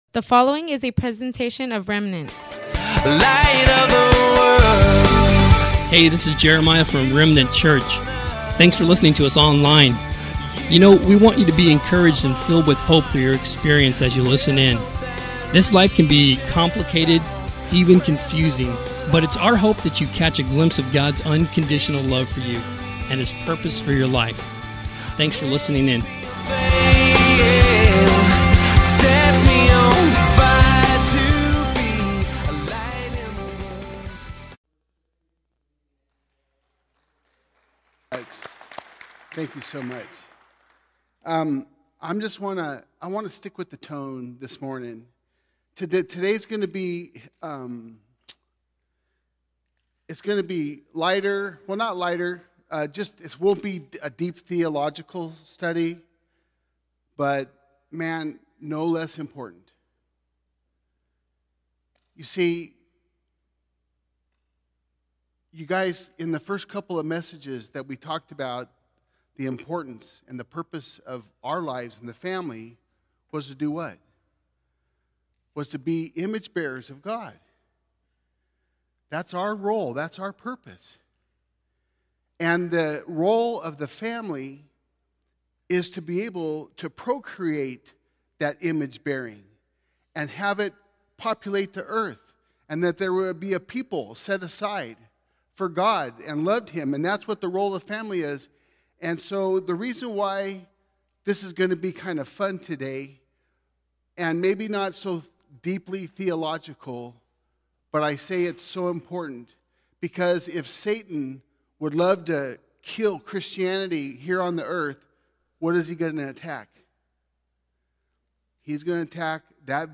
Welcome to the livestream of our worship gathering at Remnant Church in Imperial Valley, CA. Today